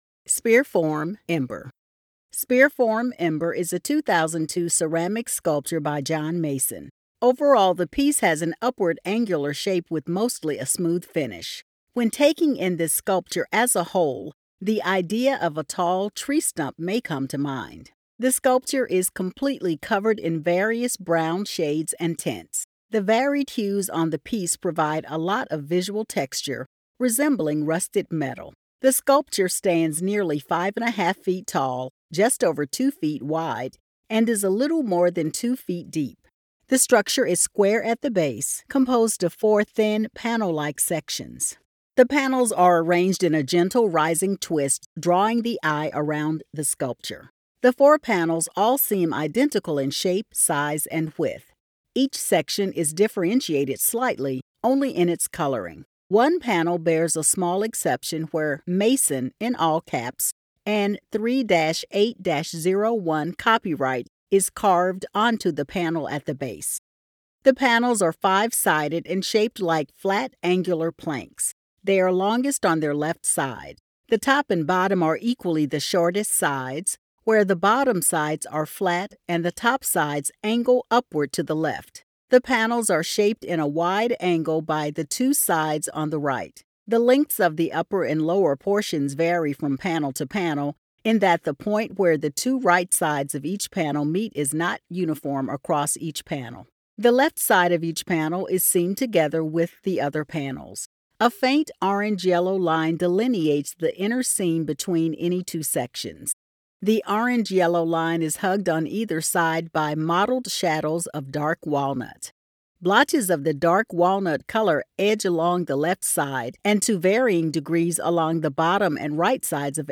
Audio Description (03:14)